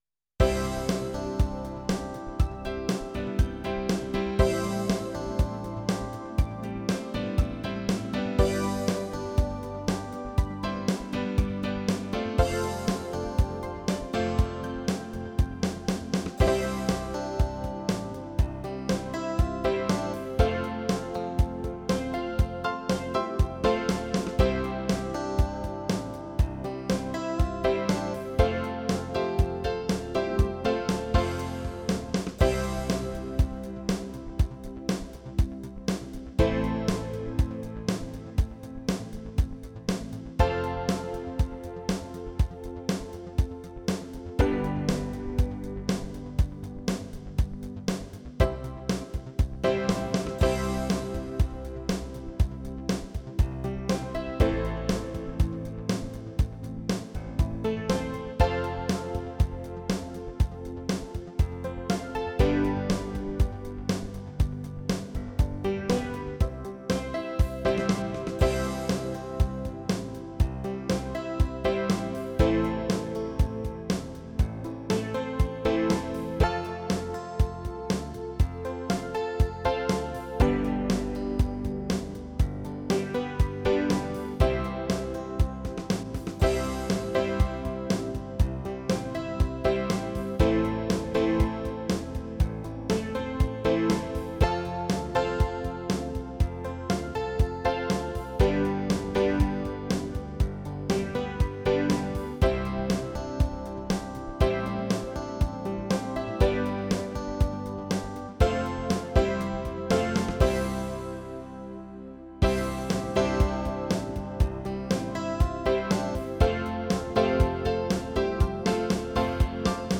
charleston
Foxtrot
groundbeat